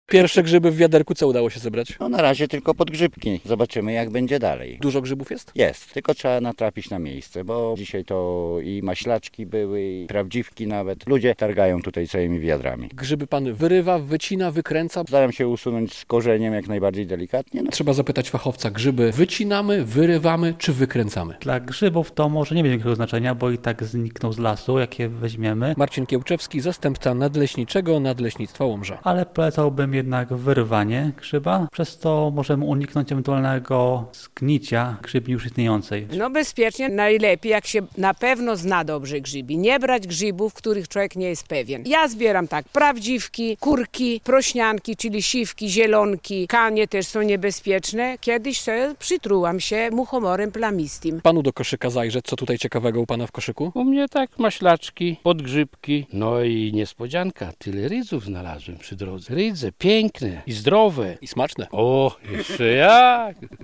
Pogoda sprzyja grzybiarzom - relacja
Spotkani w lesie koło Czerwonego Boru Grzybiarze podkreślają, że przede wszystkim trzeba zbierać grzyby, które się zna.